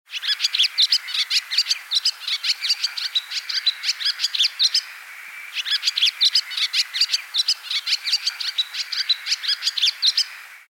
Ringtone: Swallow
Download the song of a barn swallow to use as your ringtone.